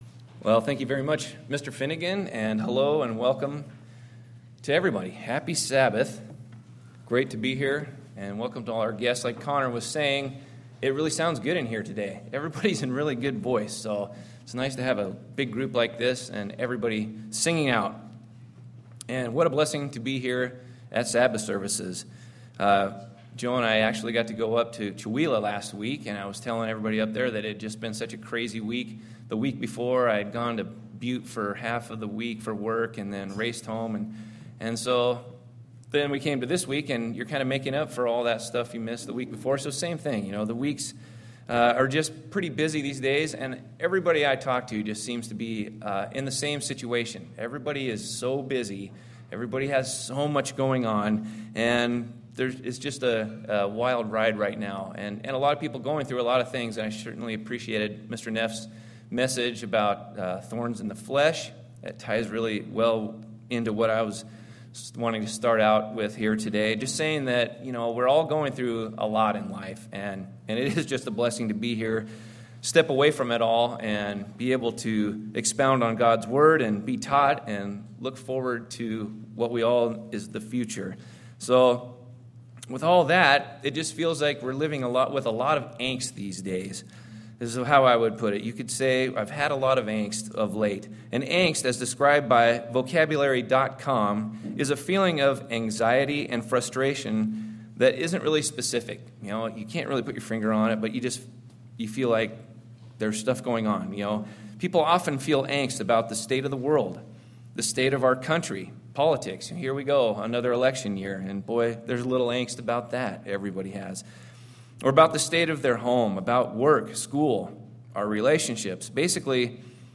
Sermons
Given in Chewelah, WA Kennewick, WA Spokane, WA